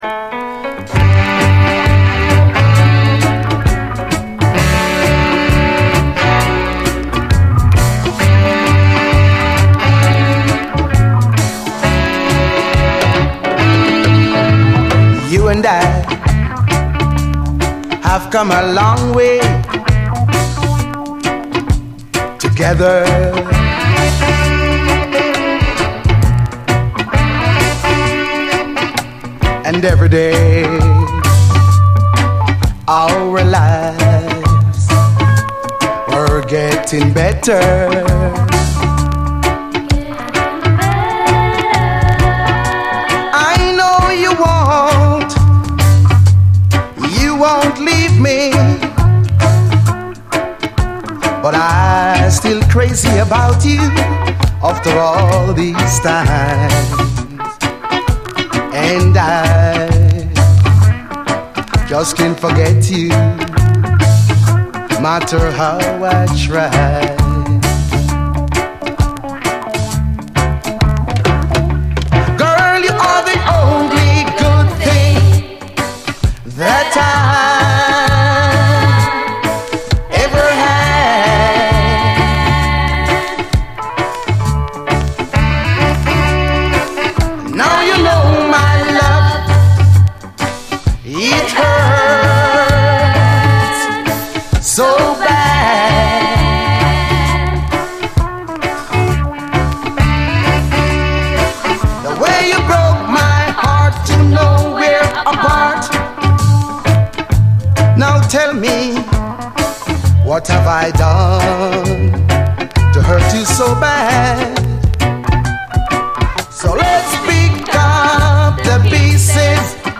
偶然か必然かフューチャー・ファンク的雰囲気のファンシー・シンセ・ブギー！